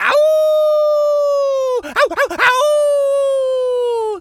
pgs/Assets/Audio/Animal_Impersonations/wolf_howl_01.wav at master
wolf_howl_01.wav